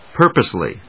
音節púr・pose・ly 発音記号・読み方
/ˈpɝpʌsli(米国英語), ˈpɜ:pʌsli:(英国英語)/